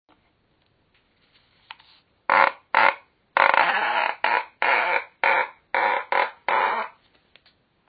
Je hoort een hard geluid als het papier langs het touw beweegt en erin knijpt.
Dit trillen klinkt als een hard geluid.